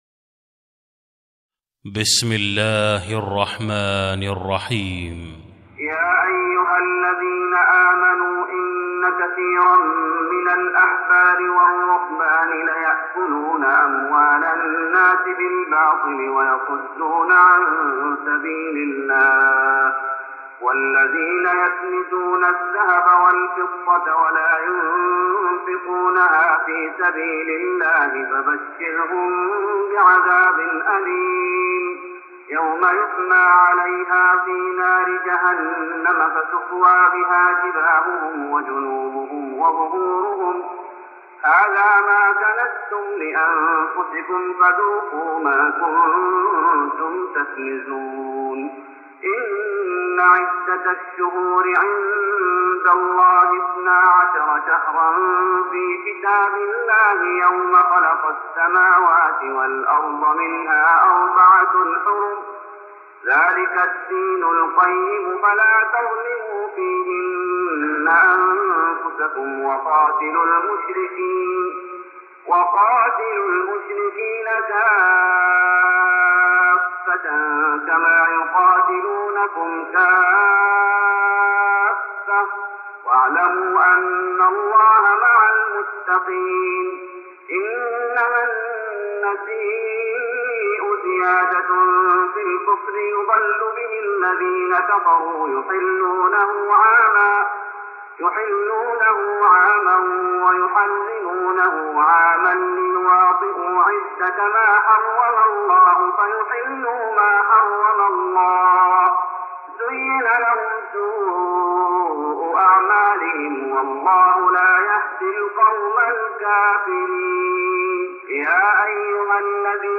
تراويح رمضان 1414هـ من سورة التوبة (34-60) Taraweeh Ramadan 1414H from Surah At-Tawba > تراويح الشيخ محمد أيوب بالنبوي 1414 🕌 > التراويح - تلاوات الحرمين